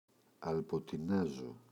αλποτινάζω [alpotiꞋnazo]